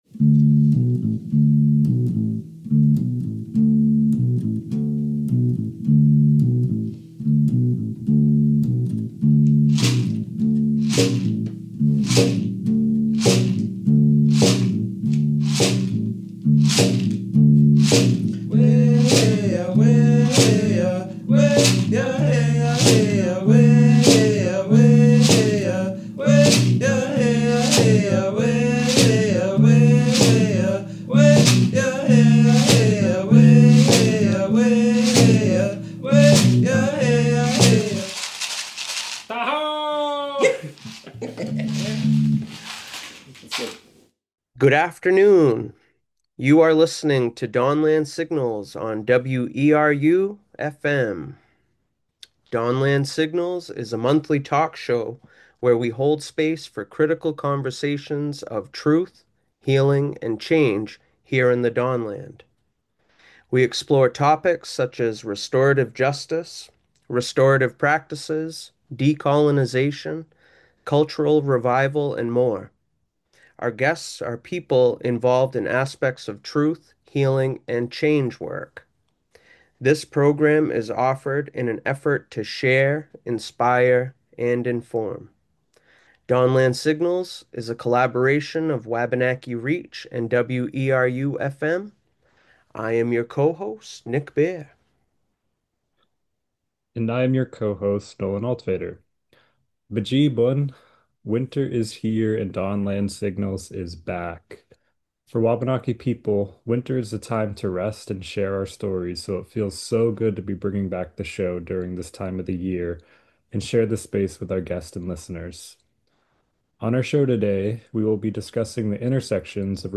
Zoom Recording